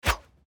monster_atk_arrow_1.mp3